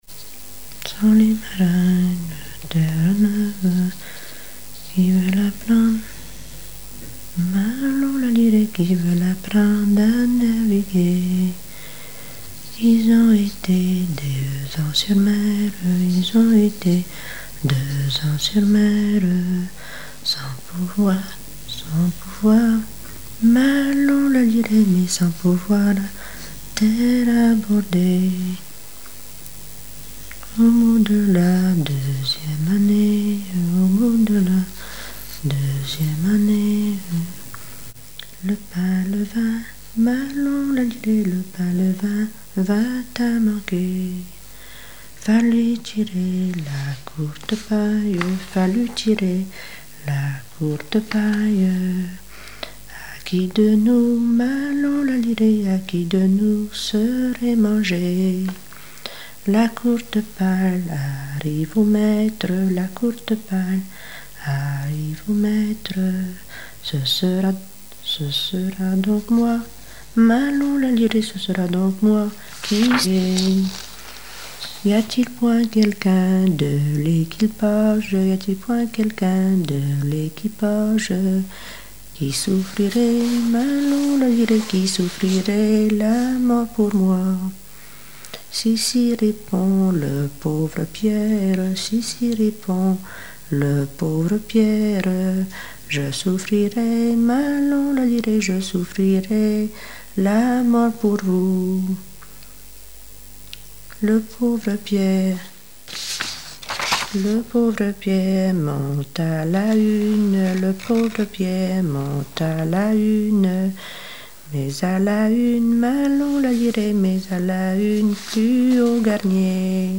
ronde : grand'danse
Recherche de chansons maritimes
Pièce musicale inédite